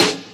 TM88 OldSnare.wav